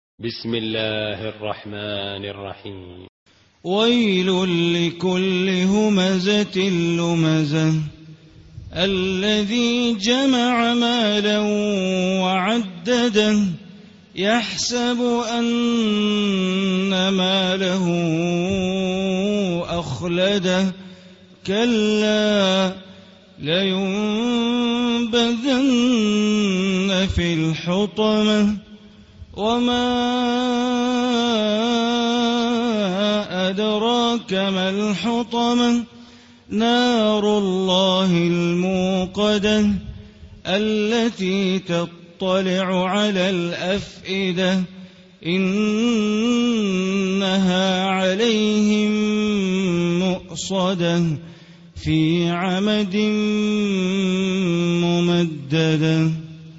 Surah Humazah Recitation by Sheikh Bandar Baleela
Surah Al Humazah, listen online mp3 tilawat / recitation in Arabic in the beautiful voice of Imam e Kaaba Sheikh Bandar Baleela.